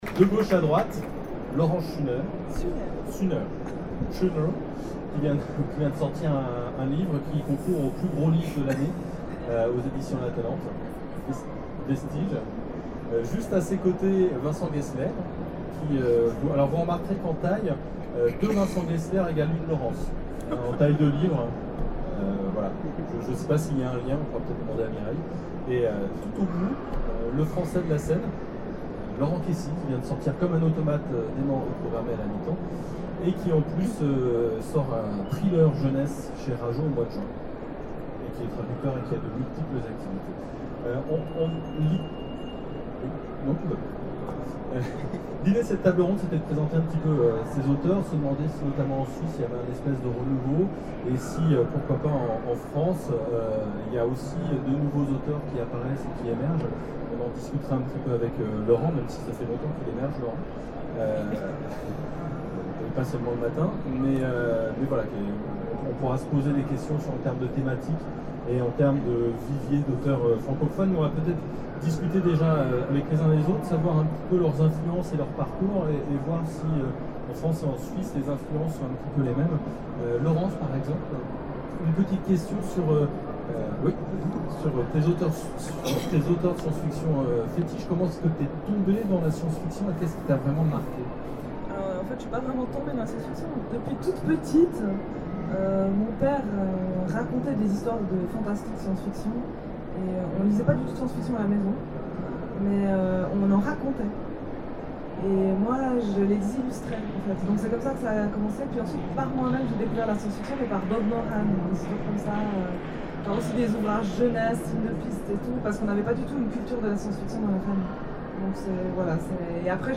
Salon du livre de Genève : conférence sur la science-fiction suisse et francophone
Conférence